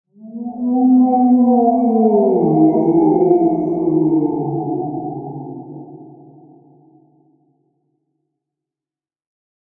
Haunting Ghost “Uuhhh” Sound Effect – Perfect For Halloween, Haunted Scenes, And Spooky Videos
Description: Haunting ghost “uuhhh” sound effect delivers a spooky and eerie ghost moan perfect for Halloween, haunted scenes, and scary videos. It creates a chilling atmosphere with mysterious and creepy tones.
Genres: Sound Effects
Haunting-ghost-uuhhh-sound-effect-perfect-for-halloween-haunted-scenes-and-spooky-videos.mp3